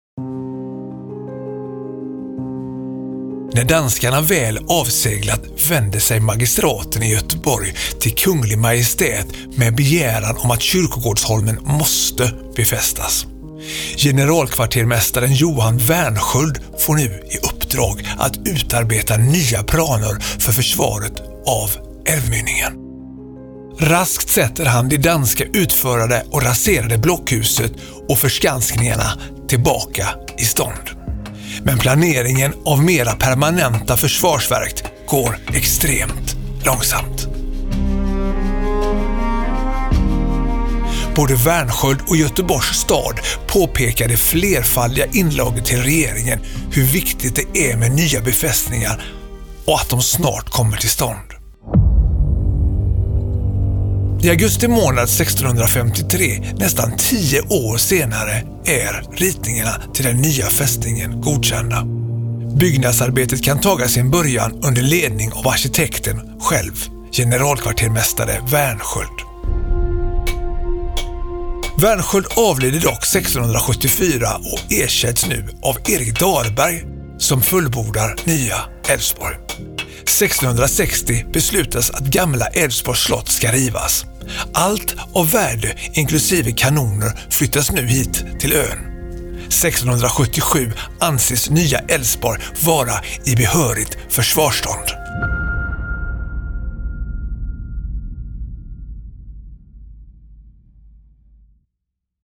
Med denna audioguide får du följa med in bakom murarna på en plats där krig, fredsförhandlingar och fängelseliv lämnat djupa spår.